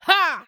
CK发力02.wav
CK发力02.wav 0:00.00 0:00.49 CK发力02.wav WAV · 42 KB · 單聲道 (1ch) 下载文件 本站所有音效均采用 CC0 授权 ，可免费用于商业与个人项目，无需署名。
人声采集素材/男2刺客型/CK发力02.wav